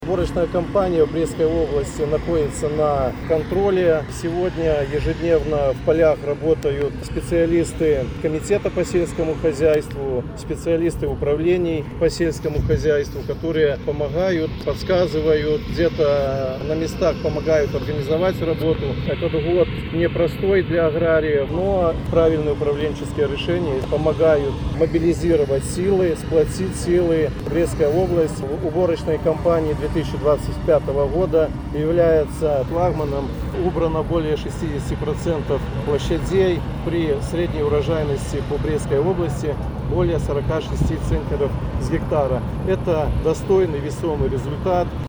Чтобы уборочная кампания в Первом регионе прошла успешно, задействованы многочисленные ведомства. Их усилия направлены на контроль процесса, поддержку и помощь аграриям, — рассказал помощник Президента — инспектор по Брестской области Александр Ломский.